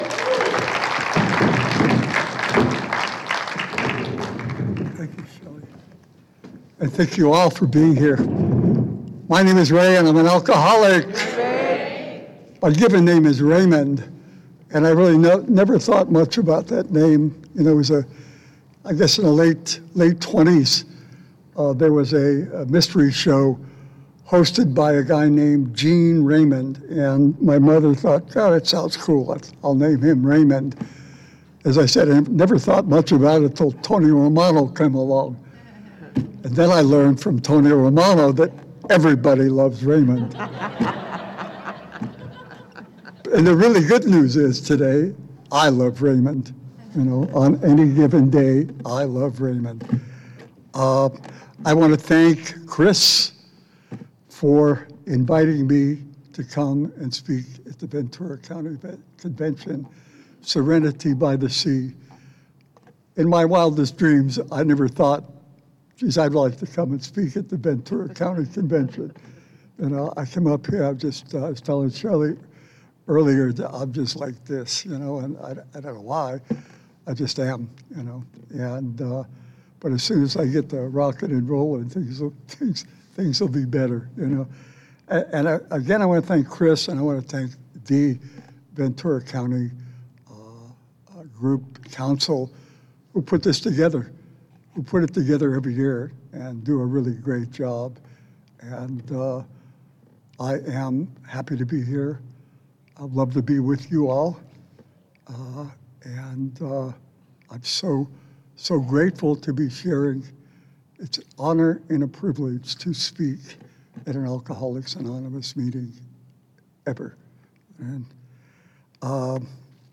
35th Annual Ventura County Serenity By The Sea